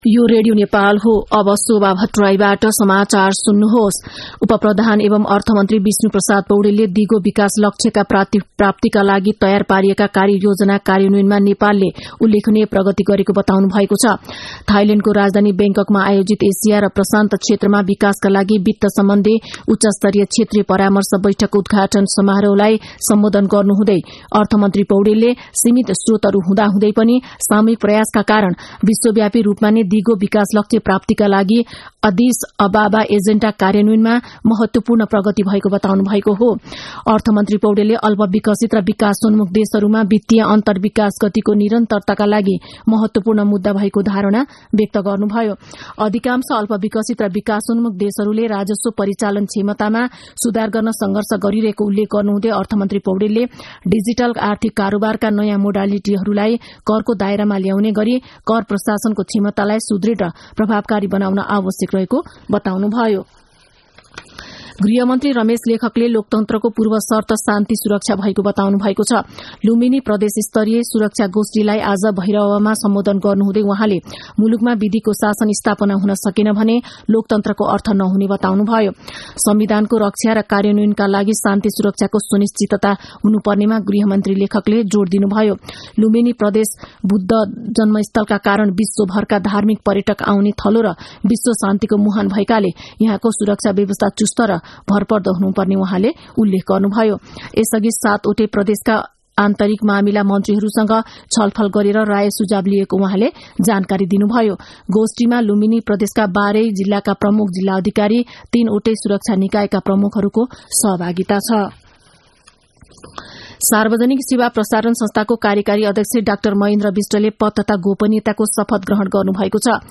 दिउँसो १ बजेको नेपाली समाचार : ३ पुष , २०८१
1-pm-nepali-news-1-13.mp3